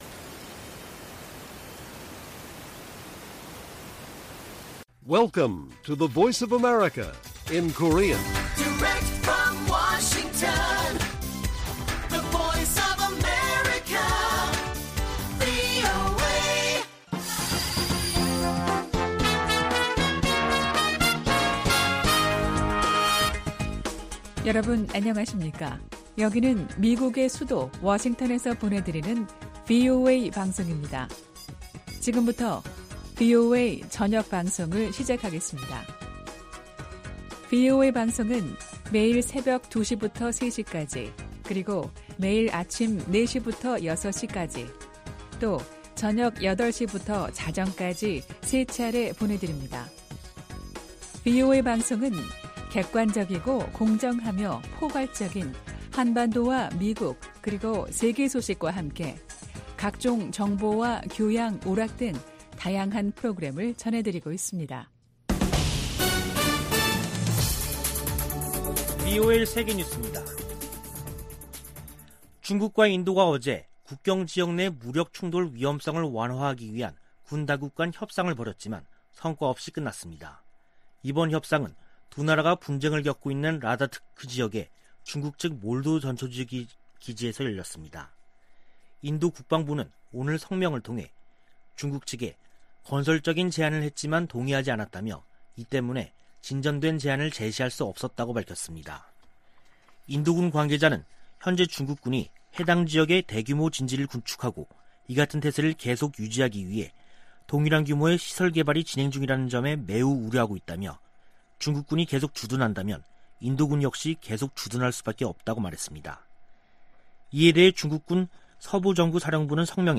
VOA 한국어 간판 뉴스 프로그램 '뉴스 투데이', 2021년 10월 11일 1부 방송입니다. 북한이 남북 통신연락선을 복원한 지 일주일이 지났지만 대화에 나설 조짐은 보이지 않고 있습니다. 미국은 지난해 정찰위성 2개를 새로 운용해 대북 정보수집에 활용하고 있다고 미 국가정찰국이 밝혔습니다. 세계 300여 개 민간단체를 대표하는 40개 기구가 10일 북한 노동당 창건 76주년을 맞아 유엔 회원국들에 공개서한을 보냈습니다.